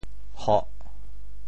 潮州 hoh4 白 对应普通话: kù 〈潮〉同“kog4②”：～好食（很好吃） | 伊生做毋然伊阿姐，不过～肖伊阿妈（她长得不似她姐姐，不过很像她妈妈）。
hoh4.mp3